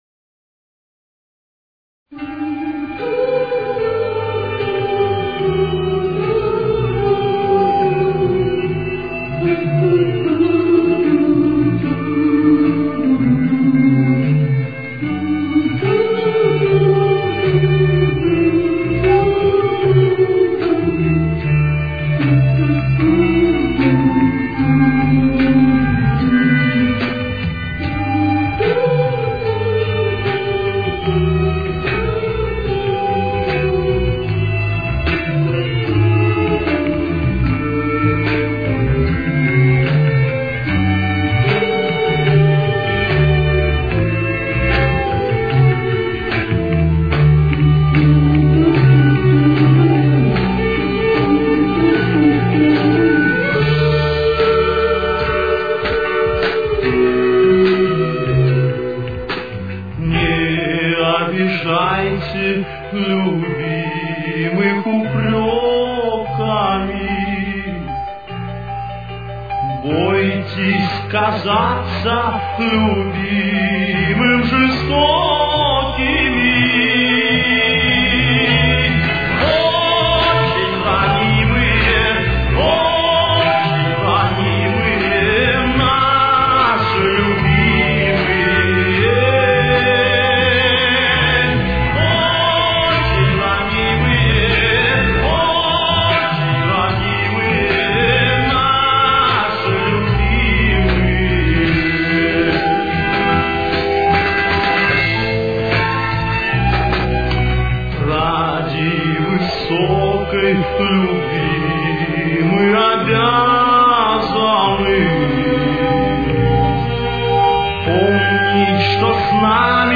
Тональность: Ми минор. Темп: 155.